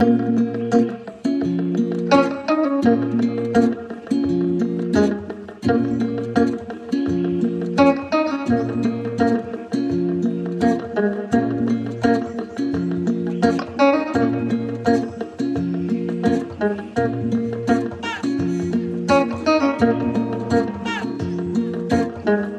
COLD 170 BPM - FUSION.wav